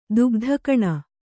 ♪ dugdha kaṇa